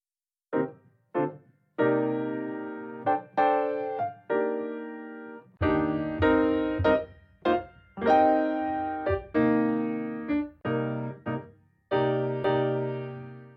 jazz.wavファイル
jazz.wav